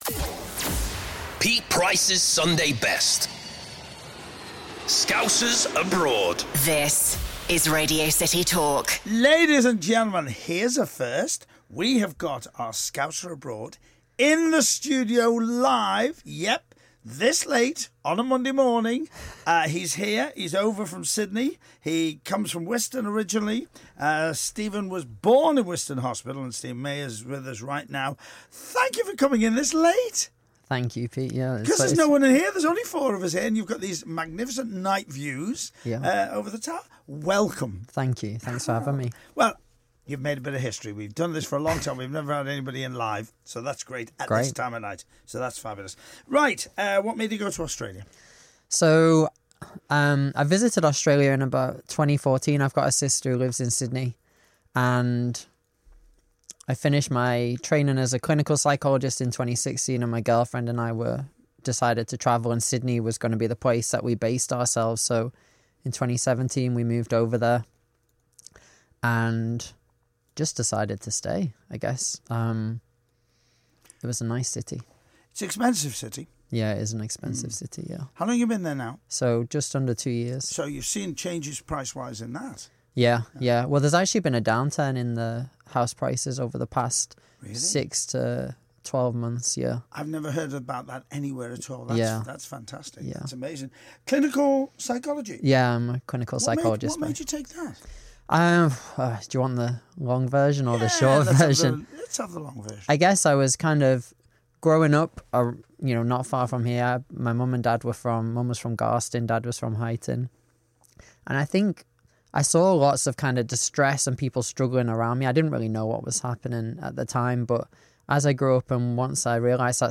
Interview on Radio City Liverpool
It was an awesome experience going to the top of the Radio City tower (see picture below) and being interviewed live.